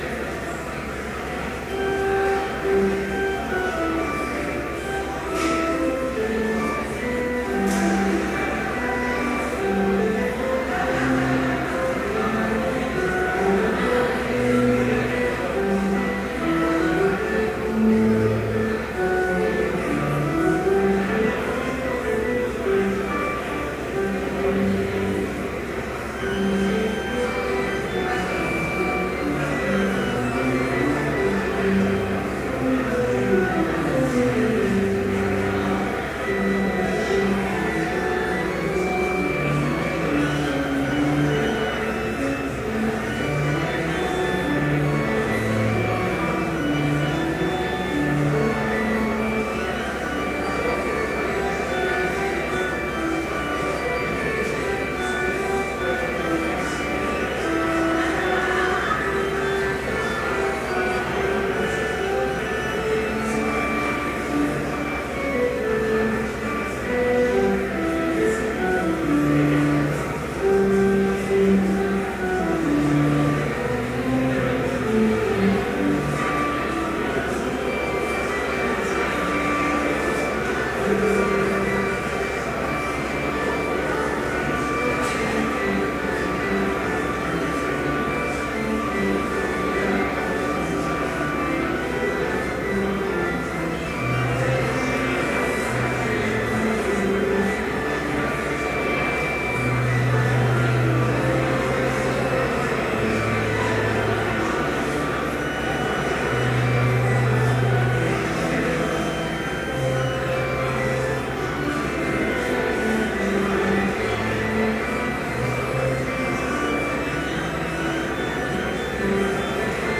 Complete service audio for Chapel - October 16, 2013